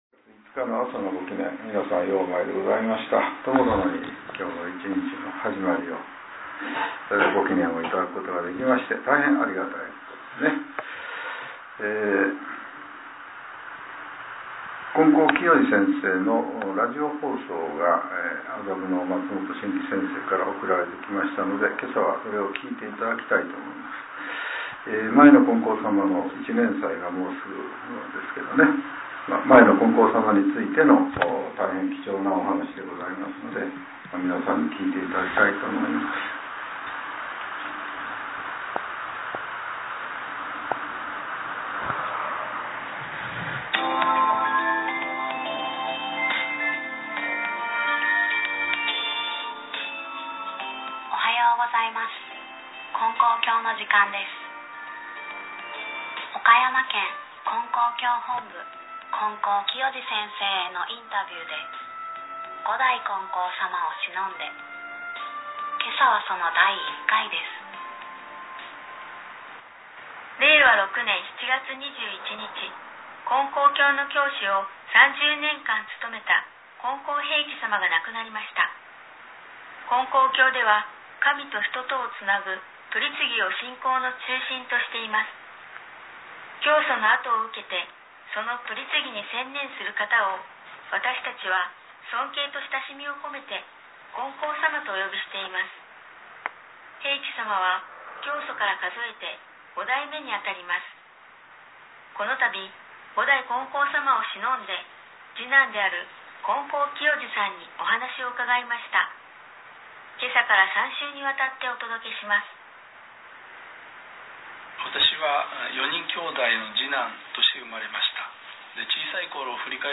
令和７年７月５日（朝）のお話が、音声ブログとして更新させれています。